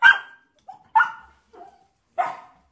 狗的庇护所
描述：记录在狗的庇护所
声道立体声